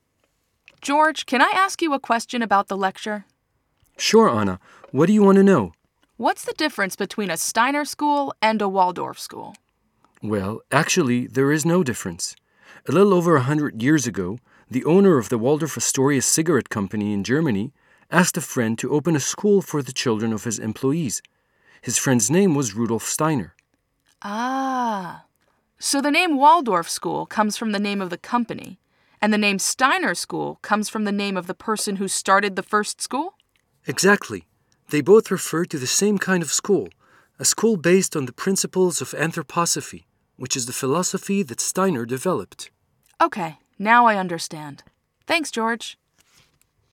Lecture/Conversation and Questions
• בכמה מקטעי השמע תשמעו אדם אחד מדבר, ובכמה מהם תשמעו שיחה בין שני אנשים - אישה וגבר.